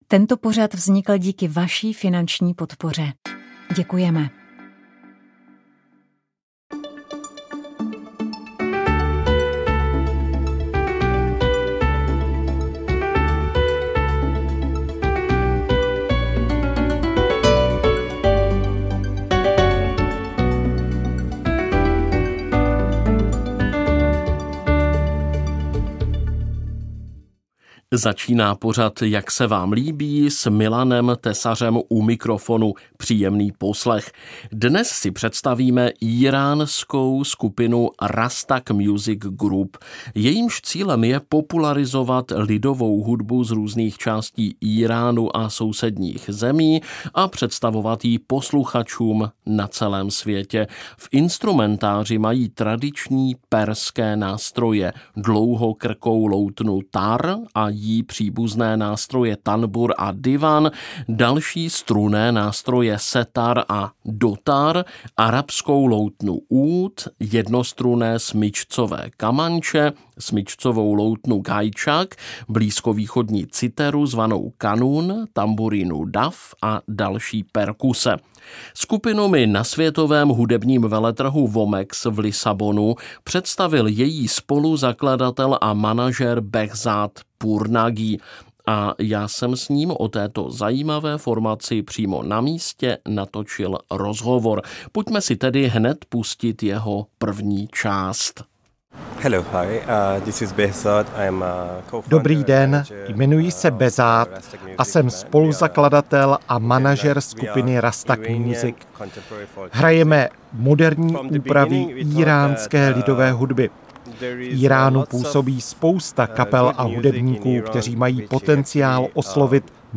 rozhovor o albu Vlčí stopy